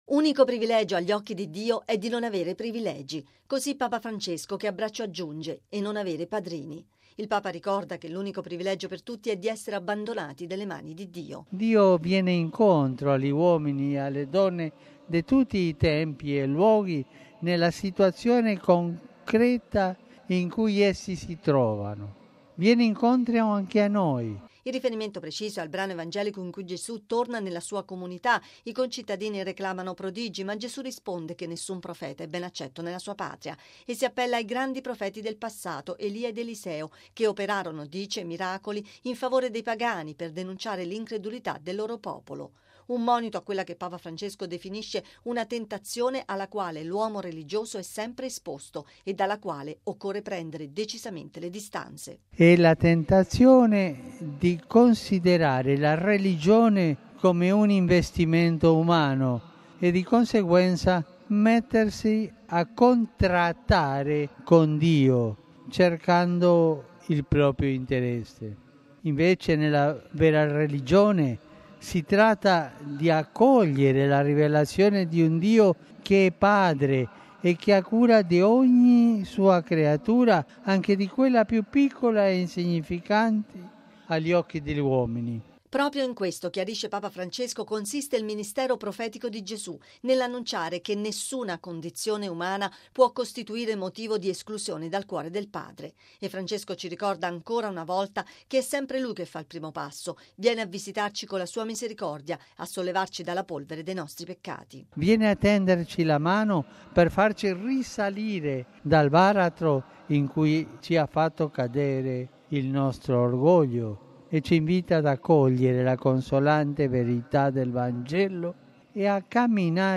Bollettino Radiogiornale del 31/01/2016
“Nessuna condizione umana può costituire motivo di esclusione dal cuore del Padre”: sono parole di Papa Francesco all’Angelus, in cui ricorda la Giornata dei malati di lebbra e saluta i ragazzi della Carovana della Pace, tradizionale appuntamento organizzato dall’Azione Cattolica.